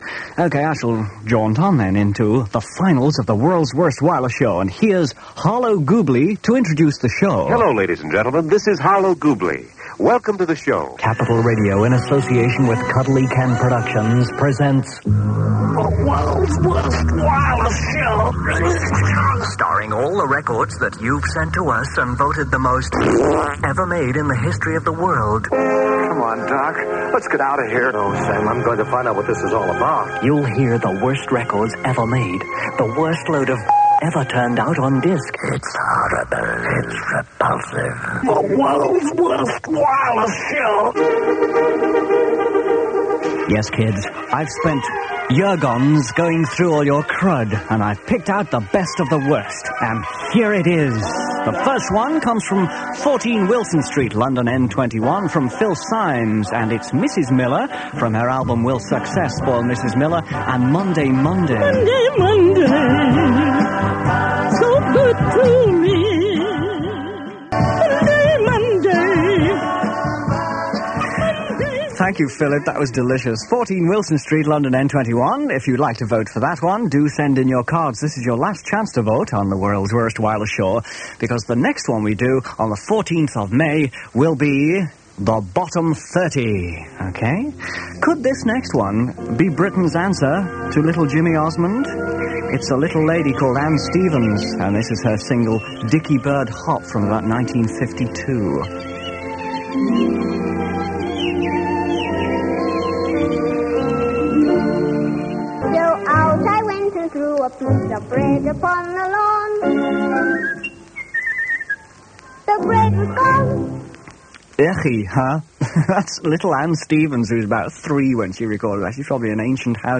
click to hear audio Kenny on an edition of “the world's worst wireless show” on London's Capital Radio, 1st May 1977 (duration 2 minutes 24 seconds)